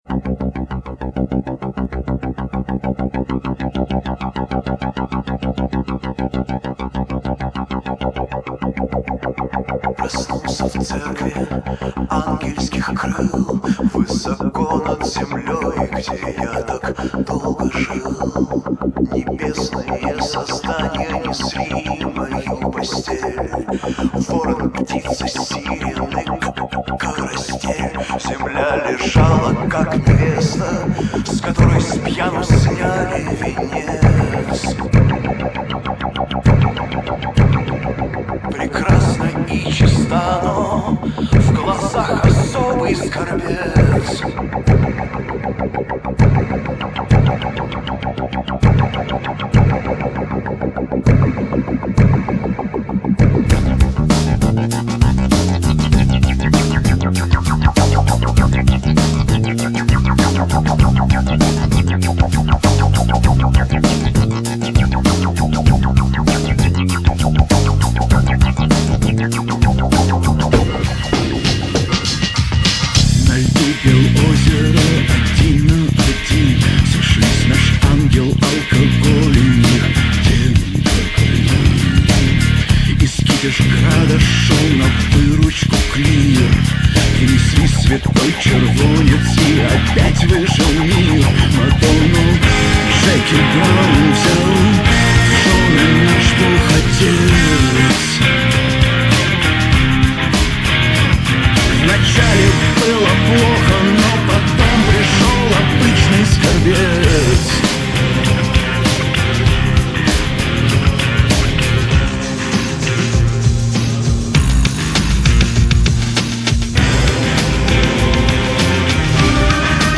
Сингл.
голос
клавишные
гитара
перкуссия
бас